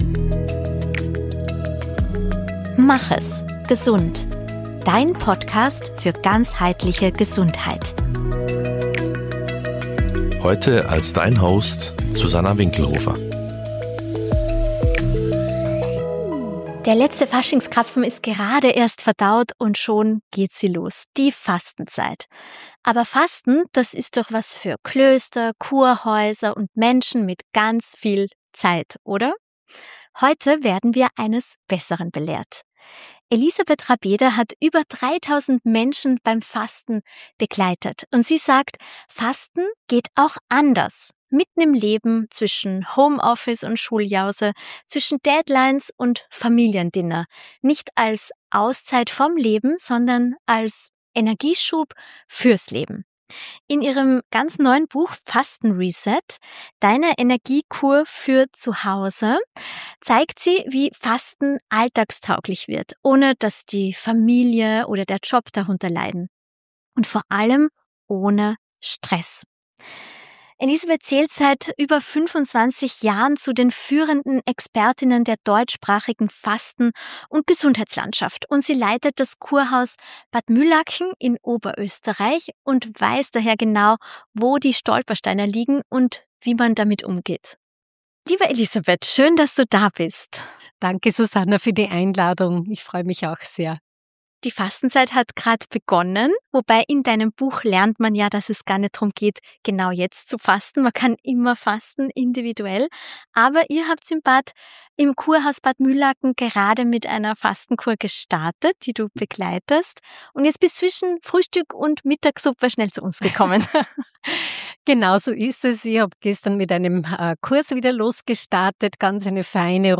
Ein Gespräch über Mut zur Einfachheit.